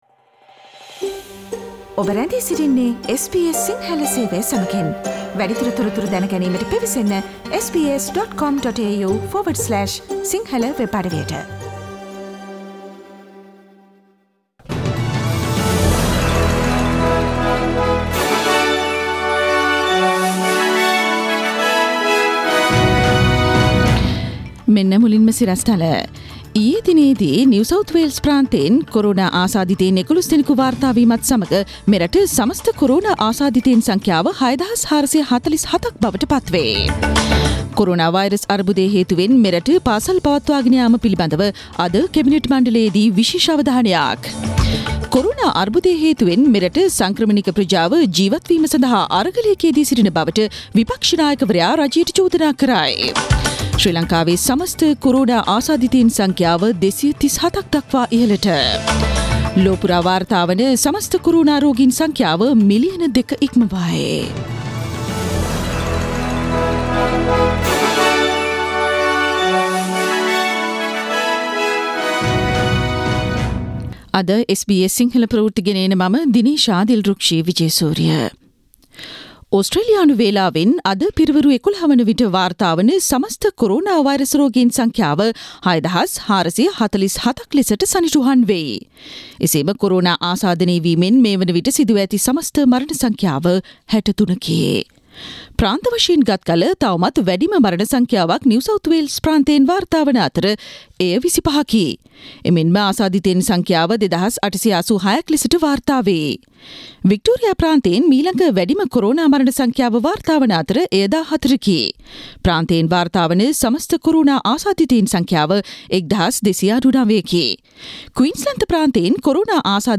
Today’s news bulletin of SBS Sinhala radio – Tuesday 16 April 2020
Daily News bulletin of SBS Sinhala Service: Thursday 16 April 2020.